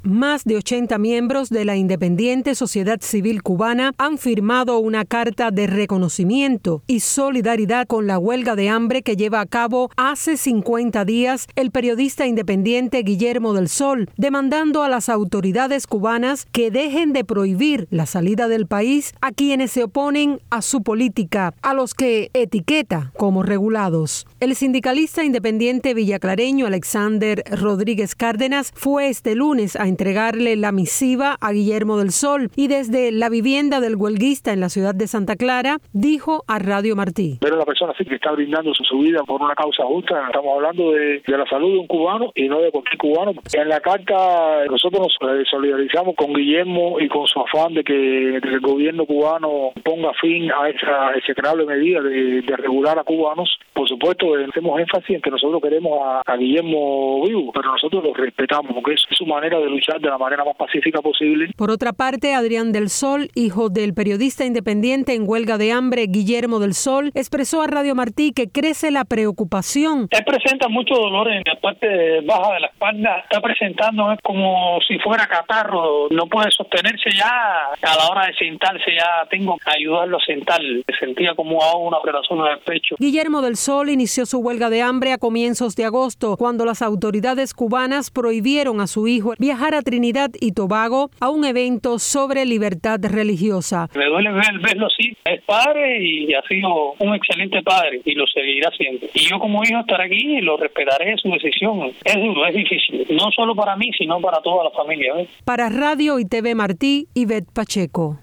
Radio Martí recogió el testimonio de dos de los firmantes